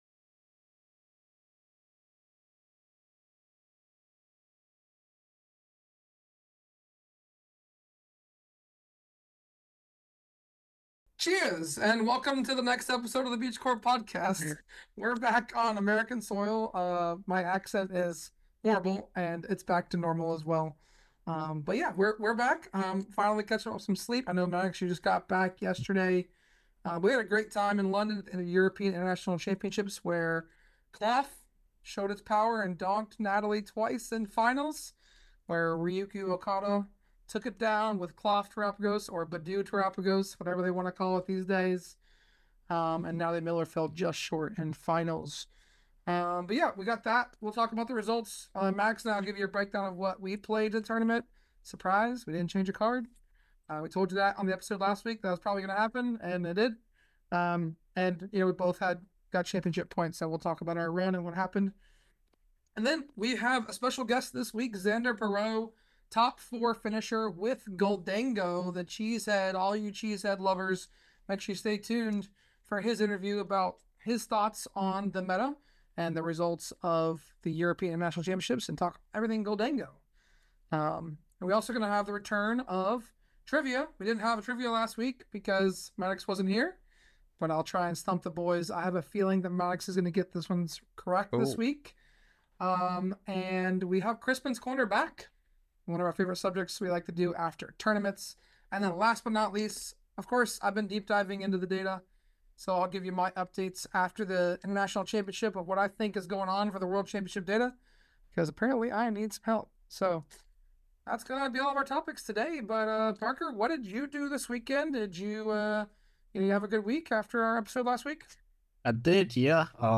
The 2nd International Championships of the 2025 season was last weekend in London! Join the Beach Court Podcast crew as they discuss what decks we chose to play and what decks performed the best.